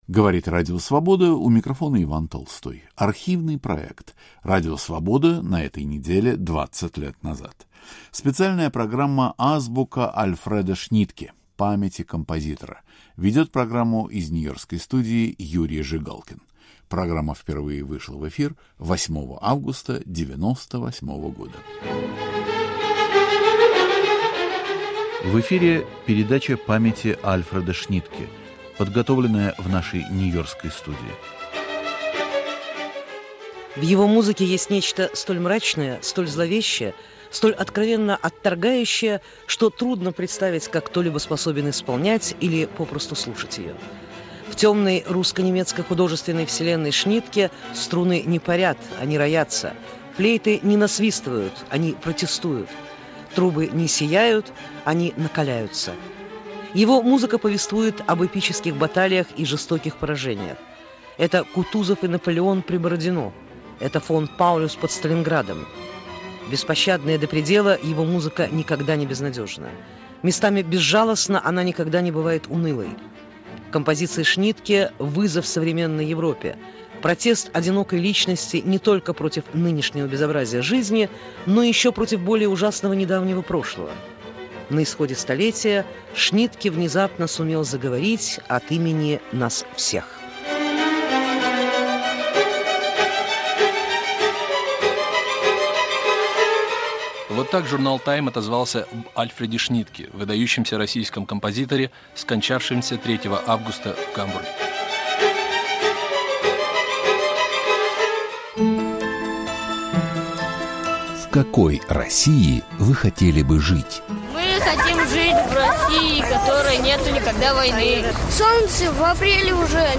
Об отношениях композитора с родиной рассказывает музыковед Соломон Волков.